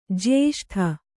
♪ jyēṣṭha